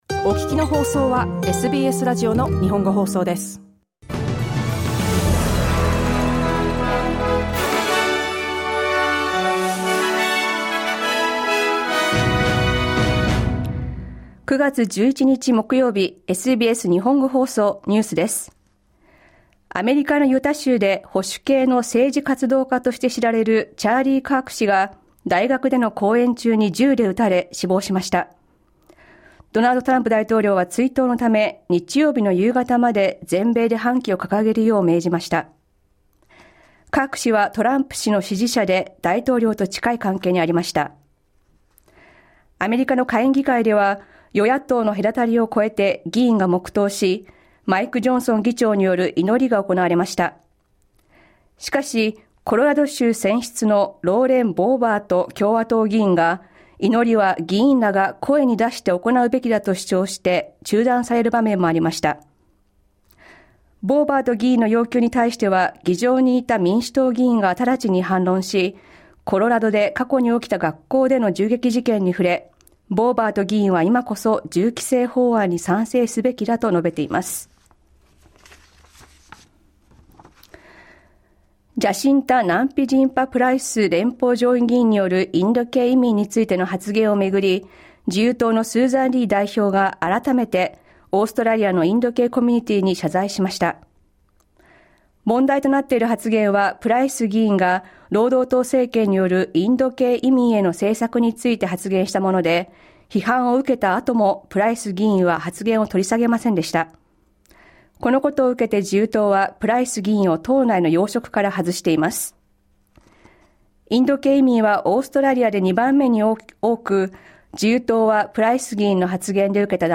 SBS日本語放送ニュース9月11日木曜日